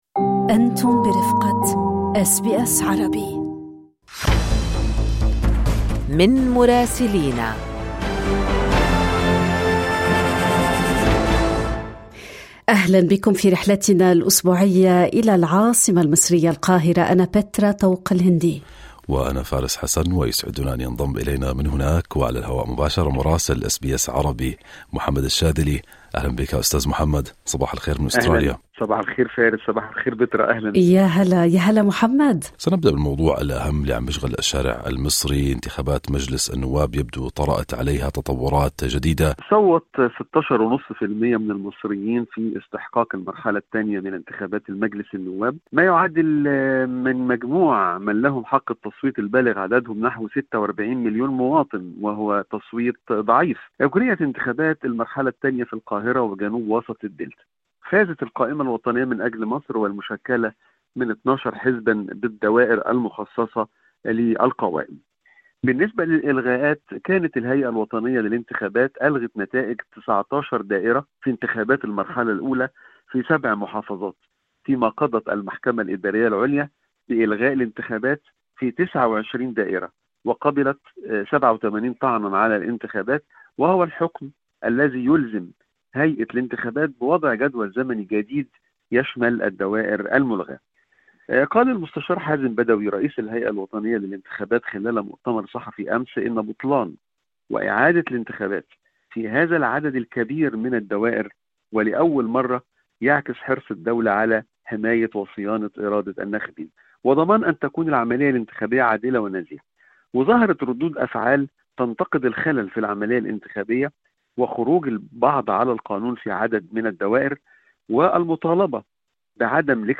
من مراسلينا: إقبال ضعيف على الانتخابات النيابية..